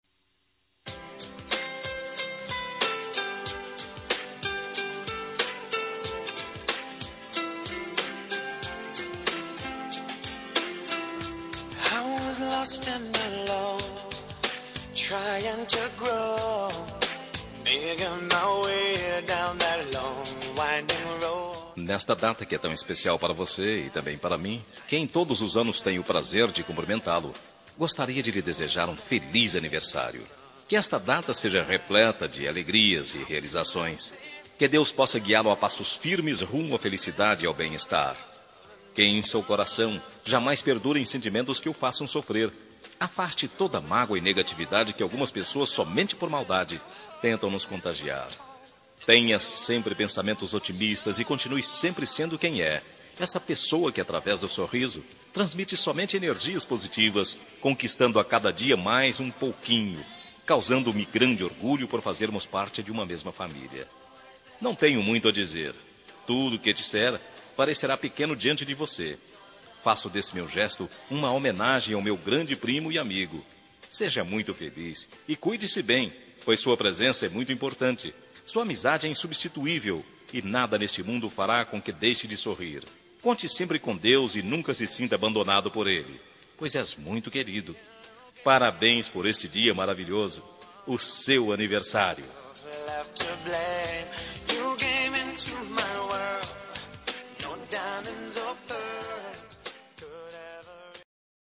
Aniversário de Primo – Voz Masculina – Cód: 042832